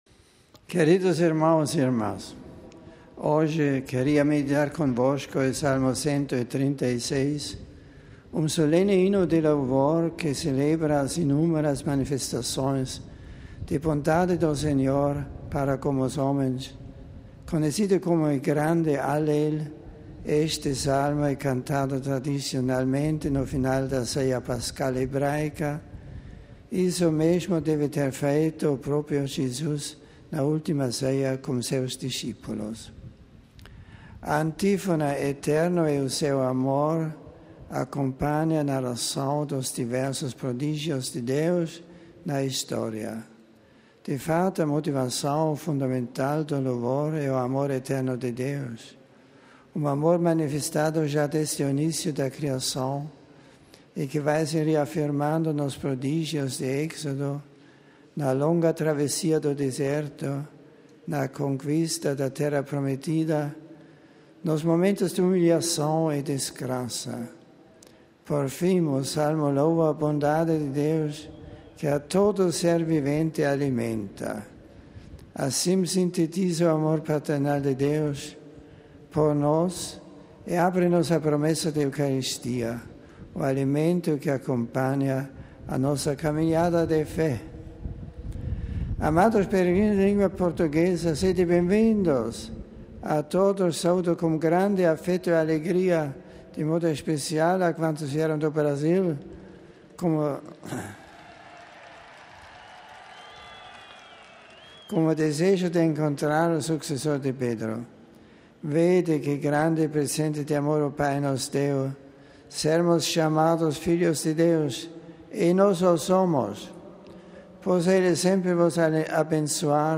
Cidade do Vaticano (RV) – Quarta-feira é dia de Audiência Geral. O céu nublado não desencorajou turistas e peregrinos, que se reuniram na Praça S. Pedro para ouvir a catequese de Bento XVI.